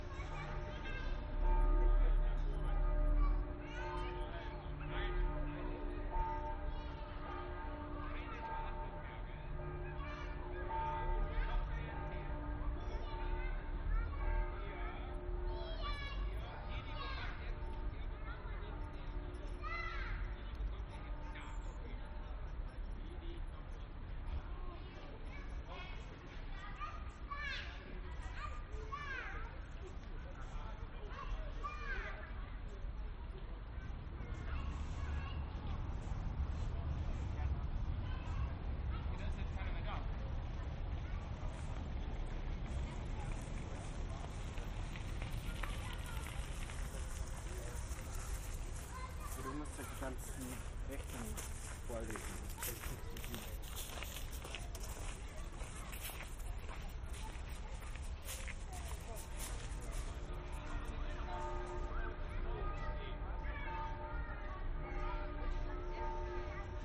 bin-park-children-loop-67s.mp3